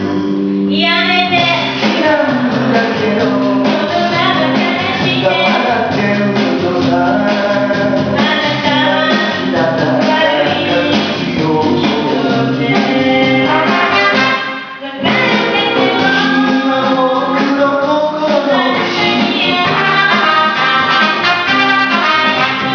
丸亀ユニオン’９９コンサート・第１部